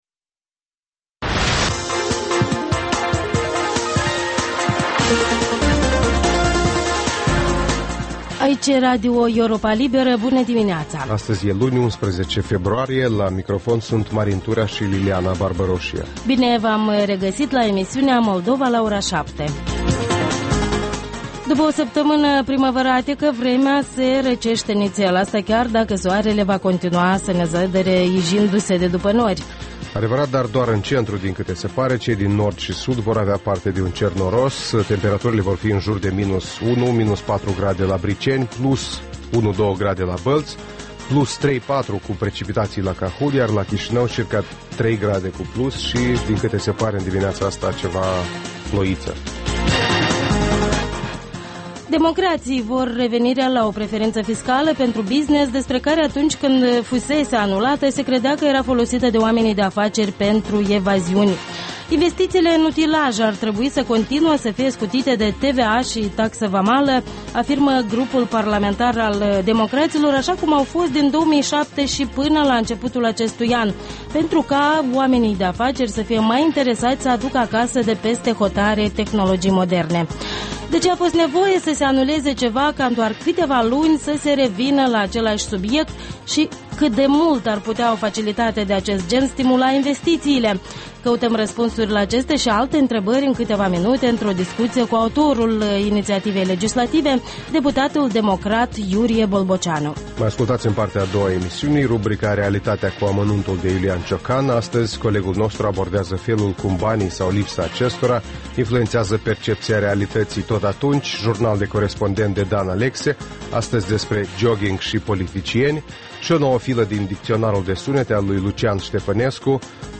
Ştiri, interviuri, analize.